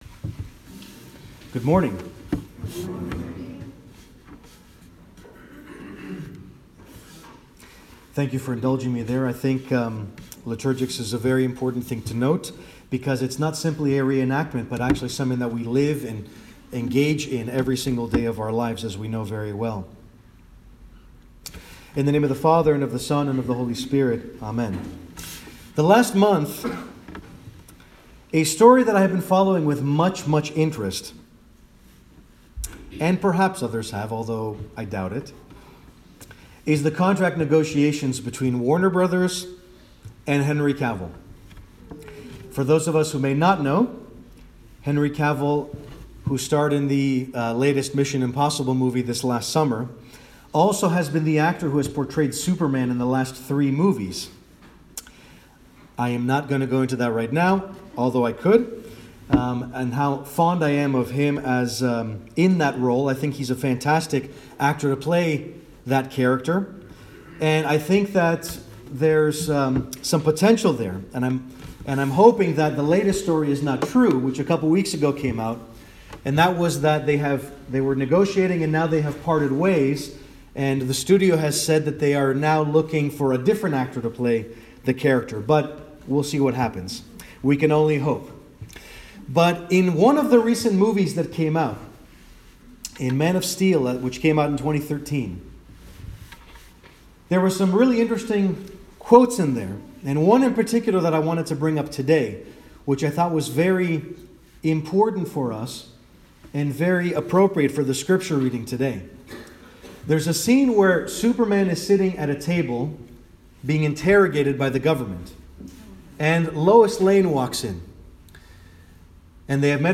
Sermon Audio- “A Symbol of Hope”
Below I’m posting the audio and transcript of a sermon I offered at my parish last October 7, 2018. The topic is hope, which of course lends itself perfectly to a certain Krypton…